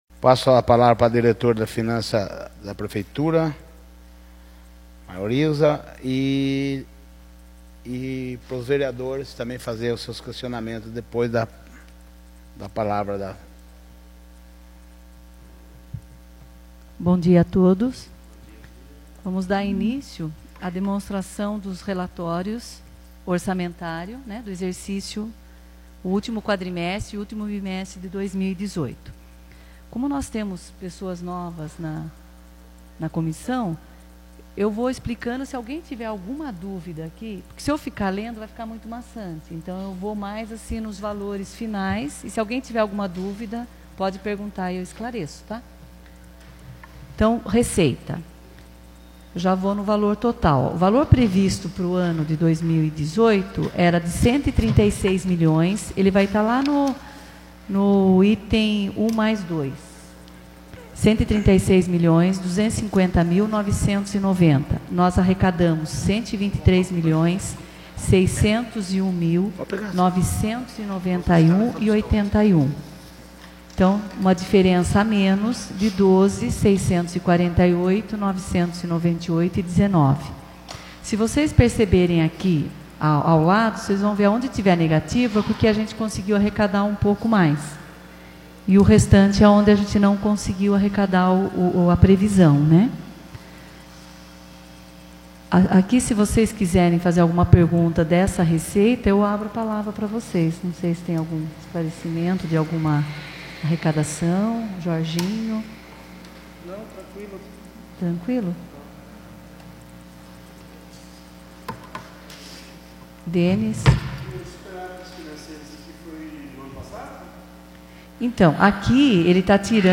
Audiência Pública das metas fiscais do 3º Quadrimestre de 2018